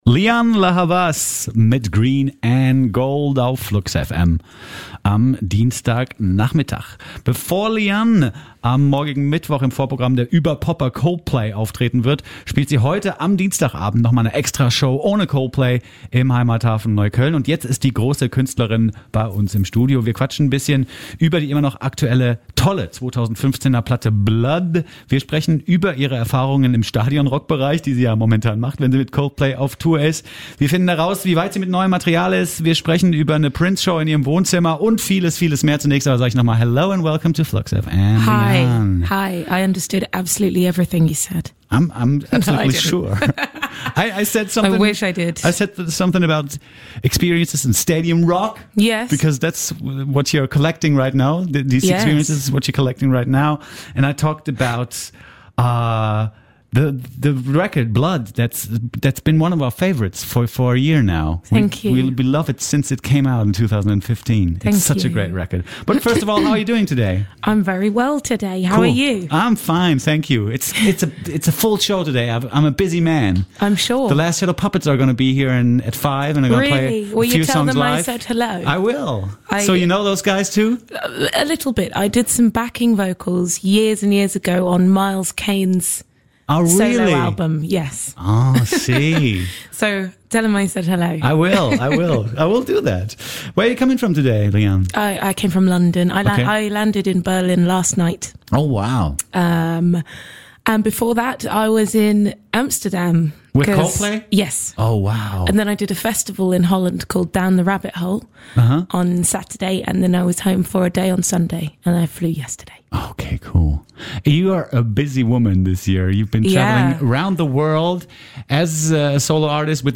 Lianne La Havas im FluxFM Interview
Lianne La Havas war letztes Jahr für den Grammy nominiert, musizierte mit Prince, steht vor Coldplay auf der Bühne - und besuchte FluxFM für ein exklusives Interview.
Hoher Besuch beehrt heute unser bescheidenes FluxFM-Studio, denn sie zählt nicht nur zum erlauchten Kreis der Musiker, die bereits für den Grammy nominiert wurden, sondern arbeitete bereits mit Prince zusammen.